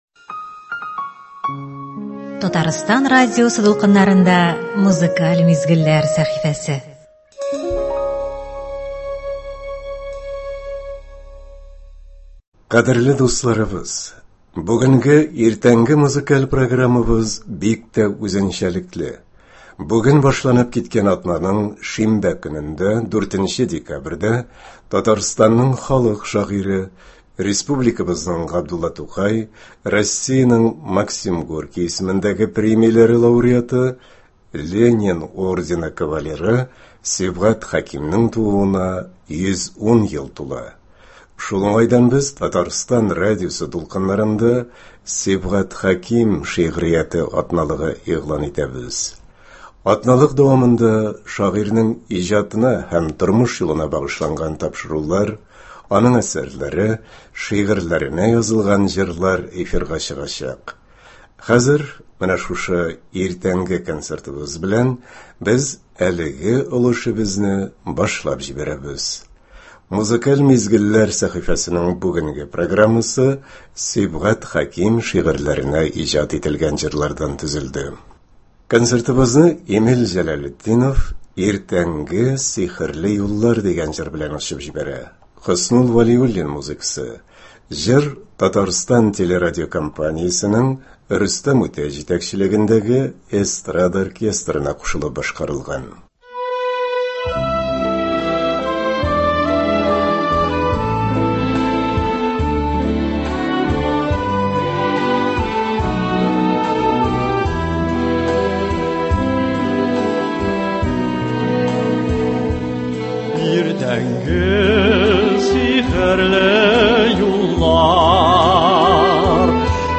Менә шушы иртәнге концертыбыз белән без әлеге олы эшебезне башлап җибәрәбез. “Музыкаль мизгелләр” сәхифәсенең бүгенге программасы Сибгат Хәким шигырьләренә иҗат ителгән җырлардан төзелде.